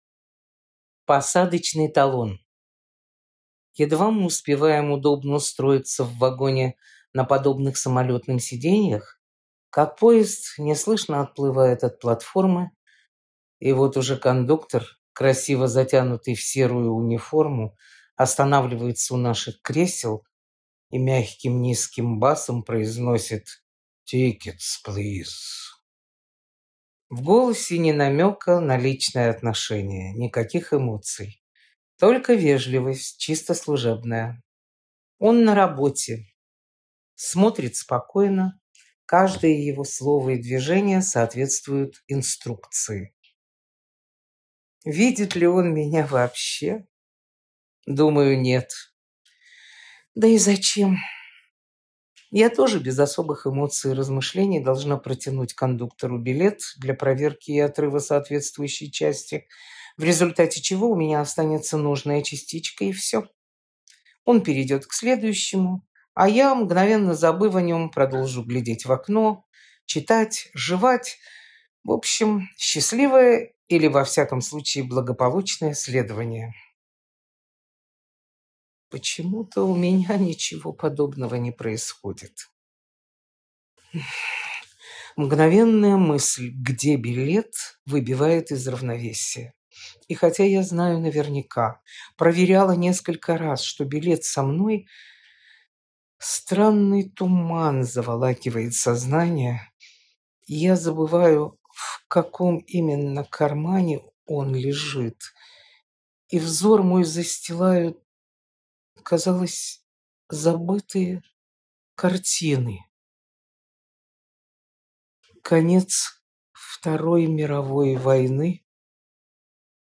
Aудиокнига Глоток вечности Автор Людмила Тобольская Читает аудиокнигу Актерский коллектив.